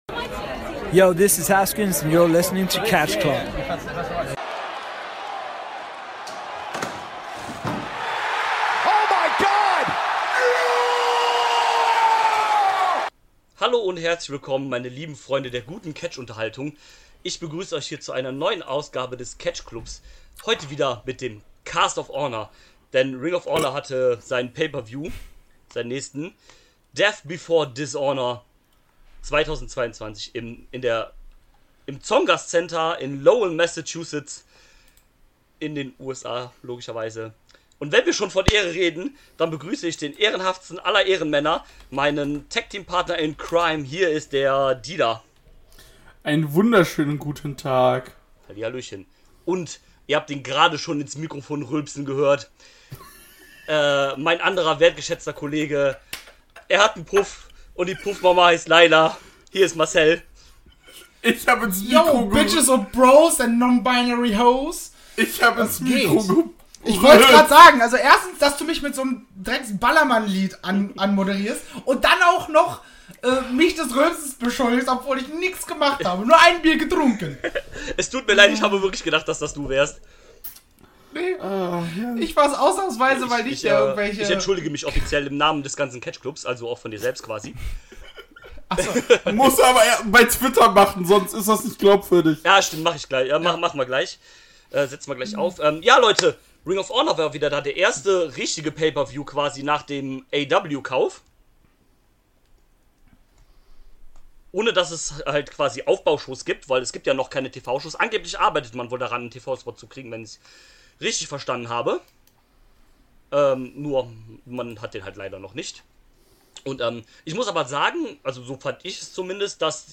Es war mal wieder soweit, ein neuer ROH PPV stand auf dem Programm. Und diesen haben wir zu 3. besprochen.